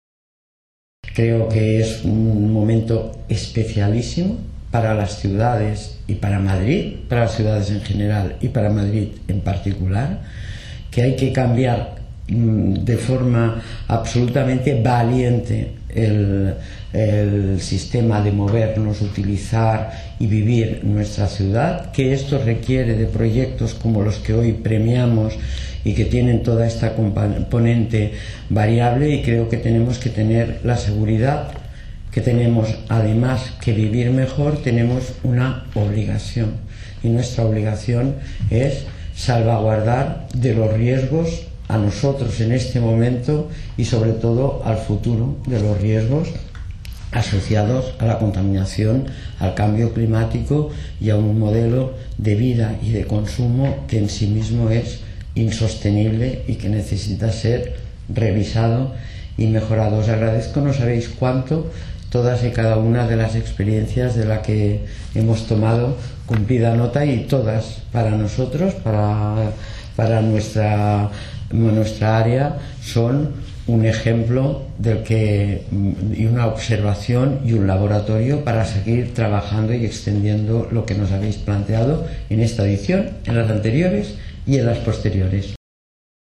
Nueva ventana:Palabras de Inés Sabanés en la entrega de premios Muévete Verde